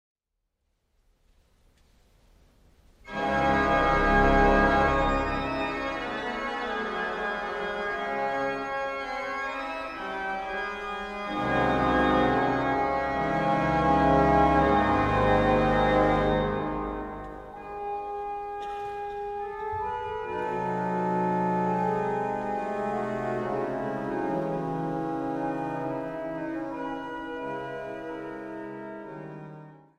klassieke werken en eigen orgelimprovisaties.